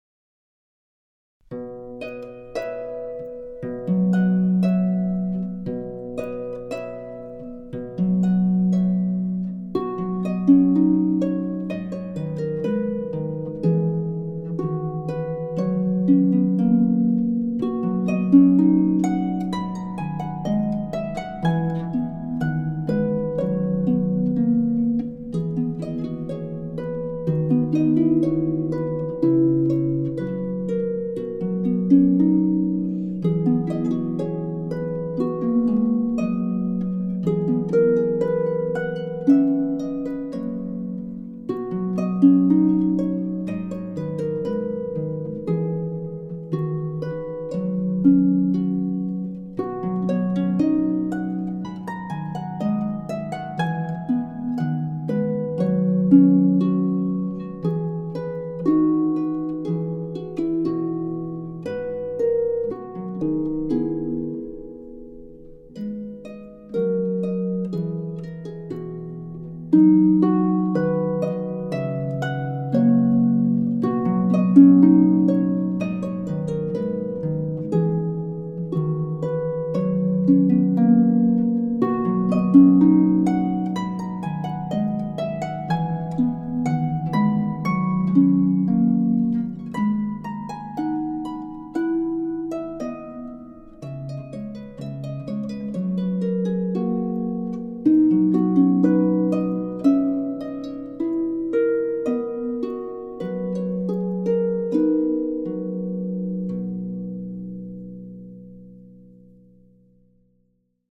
is for solo lever or pedal harp
in B-flat major.